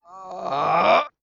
swordman_die2.wav